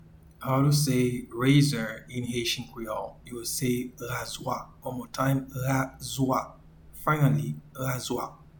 Pronunciation and Transcript:
Razor-in-Haitian-Creole-Razwa.mp3